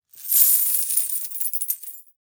coin_tons.wav